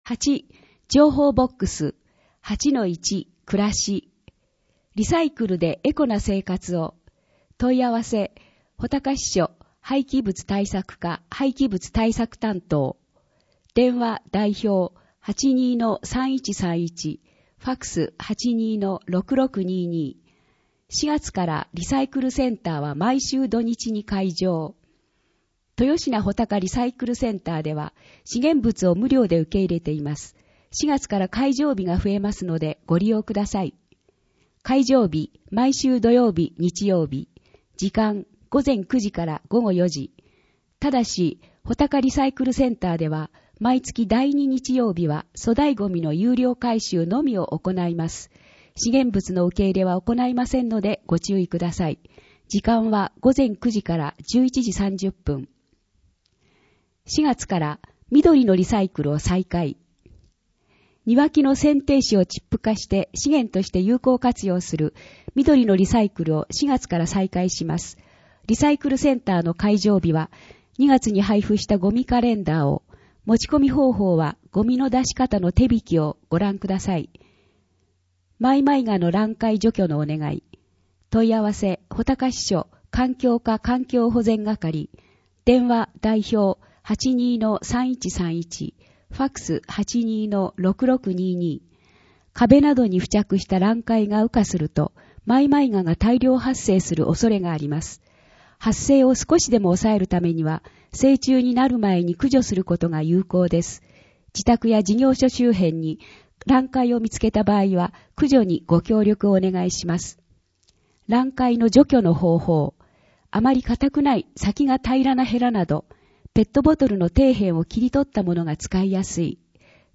広報あづみの朗読版204号(平成27年3月18日発行) - 安曇野市公式ホームページ
「広報あづみの」を音声でご利用いただけます。この録音図書は、安曇野市中央図書館が制作しています。